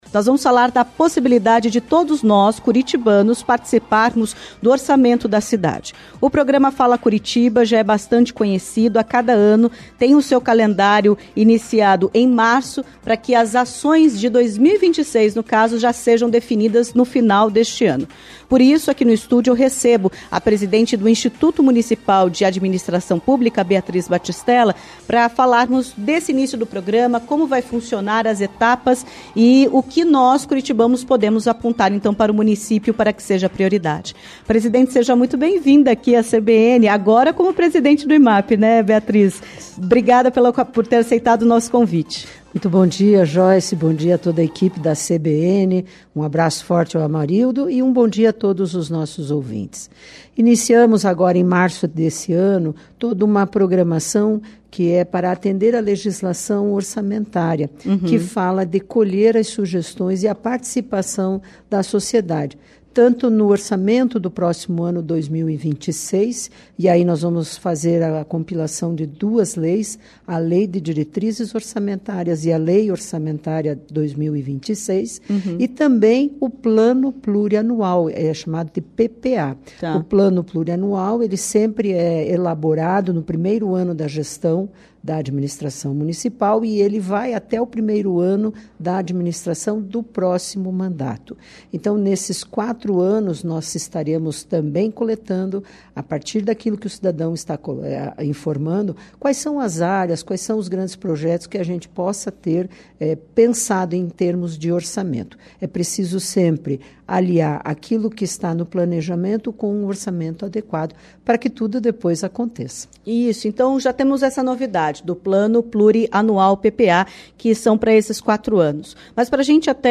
Em entrevista à CBN Curitiba, a presidente do Instituto Municipal de Administração Pública (IMAP), Beatriz Batistella, explicou como funciona o Programa Fala Curitiba, quais fases da iniciativa e como os curitibanos podem participar.